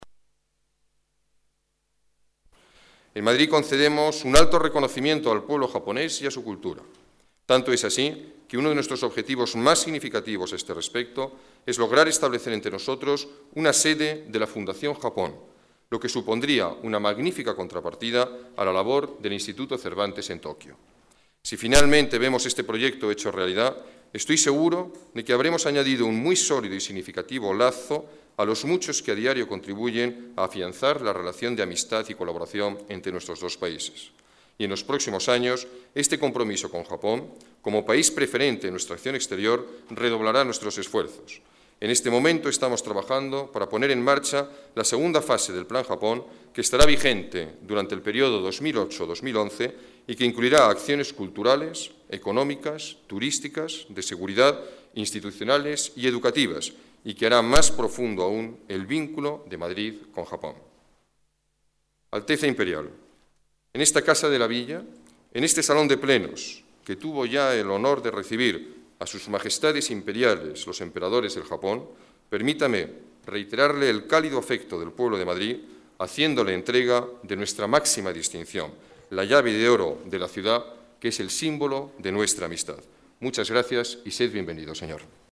Nueva ventana:Declaraciones del alcalde sobre la cooperación Madrid-Japón